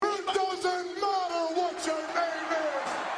it doesnt matter what your name is Meme Sound Effect
Category: Sports Soundboard